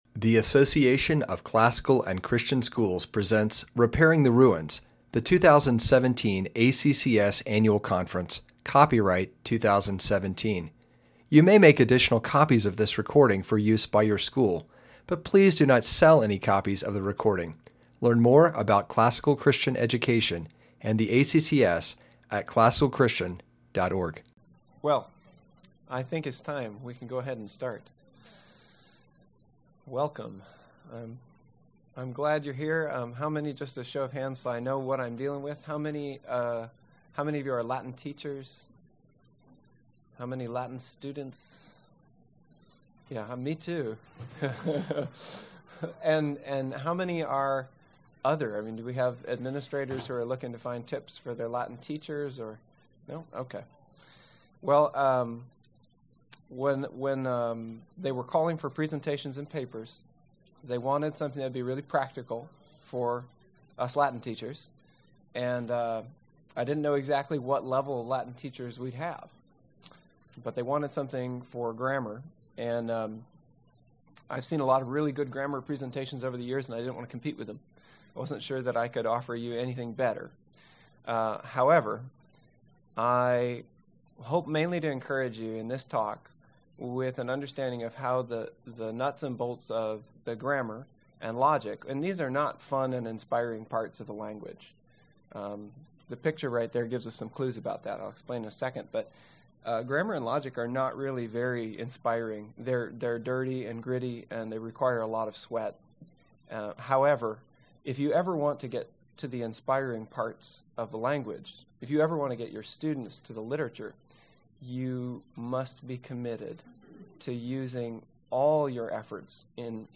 2017 Workshop Talk | 0:58:09 | All Grade Levels, Latin, Greek & Language, Rhetoric & Composition
In this workshop for Latin teachers, participants will learn how to help students make the transition from grammar (the raw materials of Latin) to rhetoric (creative composition). Practical methods, exercises, and strategies will be shared, along with time for Q & A.